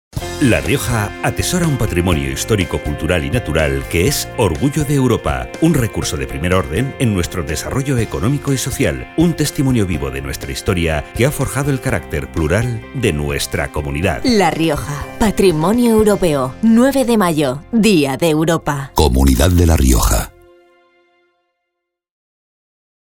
Elementos de Campaña Cuñas radiofónica Cuña de 20".